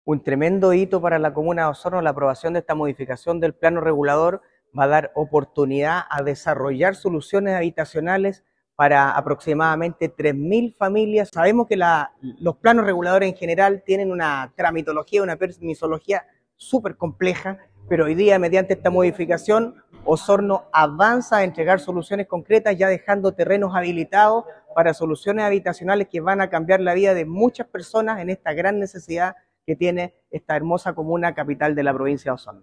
Asimismo, el consejero regional Cristian Vargas celebró la modificación, al señalar que se trata de un hito importante que abre nuevas oportunidades para el desarrollo de la comuna. Vargas destacó que, además de la construcción de viviendas, la aprobación permitirá habilitar nuevos terrenos para proyectos futuros.